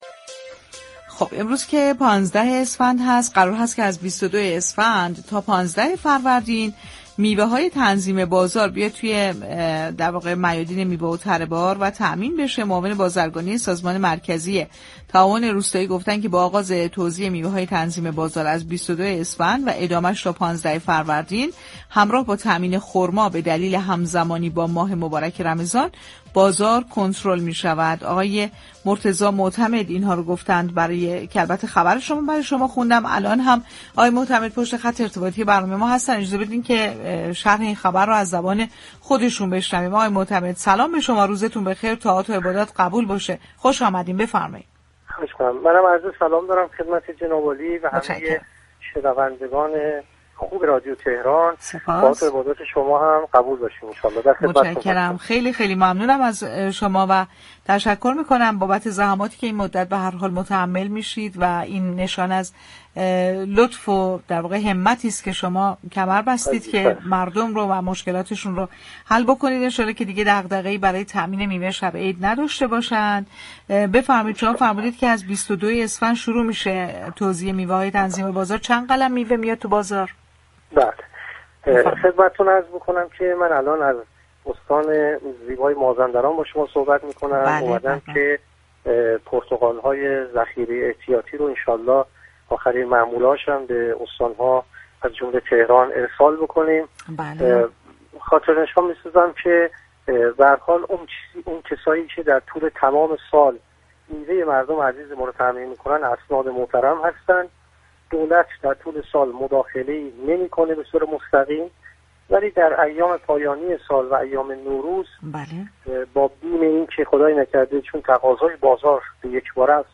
معاون فنی و بازرگانی سازمان مركزی تعاون روستایی در گفت‌وگوبا رادیوتهراناظهارداشت: عرضه میوه و خرما از 22 اسفند در كلانشهرها و 24 اسفند در شهرستان‌ها آغاز و تا 15 فروردین ادامه دارد، در صورتی كه میوه مازاد داشته باشیم طرح عرضه میوه تنظیم بازار تا اردیبهشت ادامه خواهد داشت.